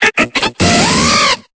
Cri d'Iguolta dans Pokémon Épée et Bouclier.